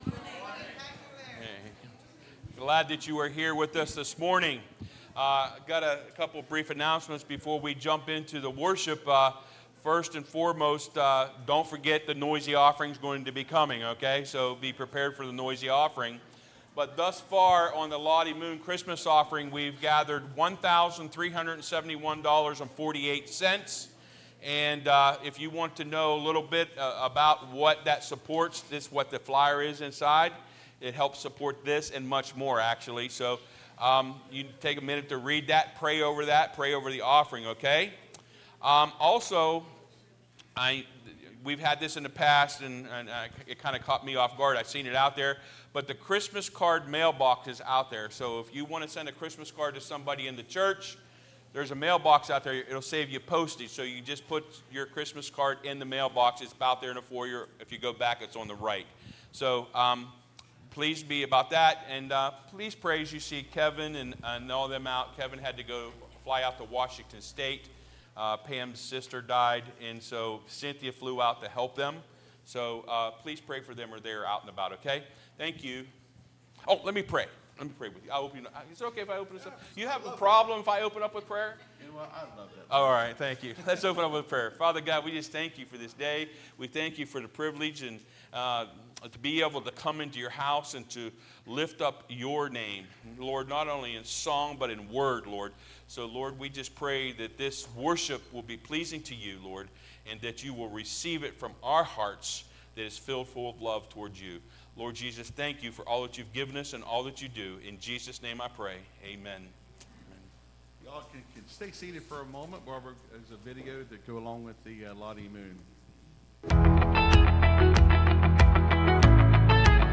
(Sermon starts at 21:30 in the recording).